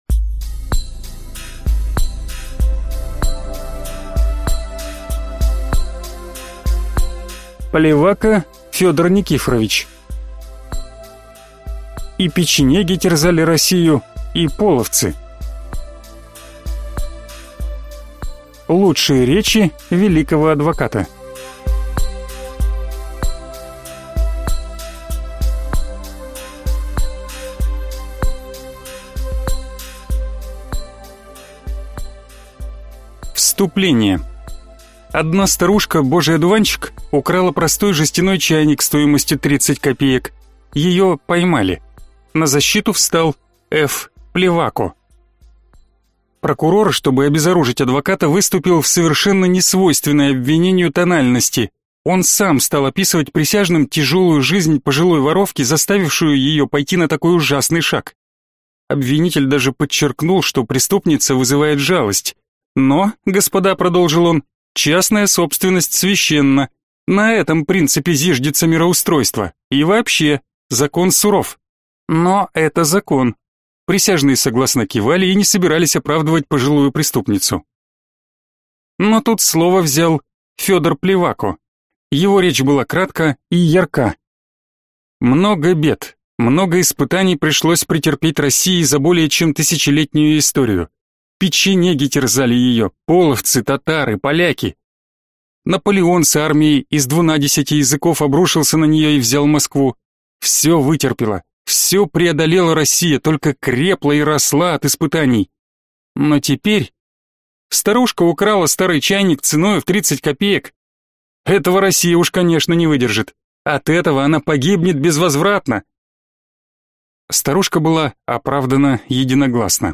Аудиокнига И печенеги терзали Россию, и половцы. Лучшие речи великого адвоката | Библиотека аудиокниг